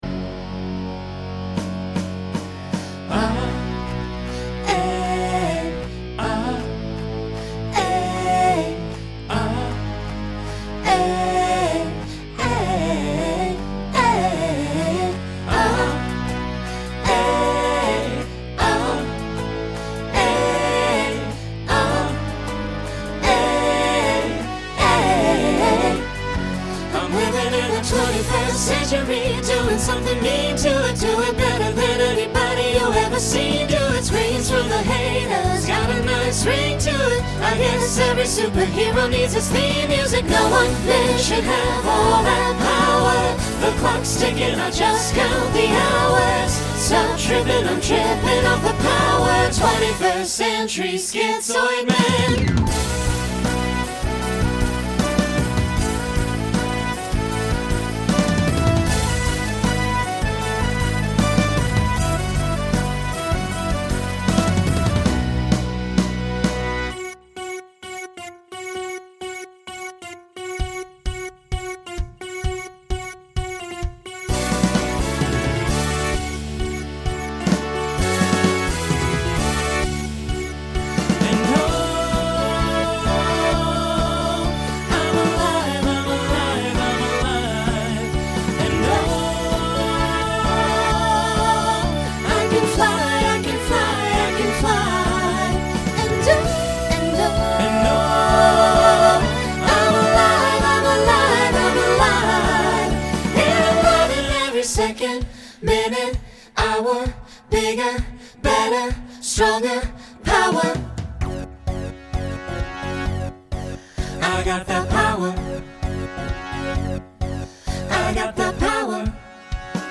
New SAB voicing for 2025.
Pop/Dance , Rock
Voicing SAB , SATB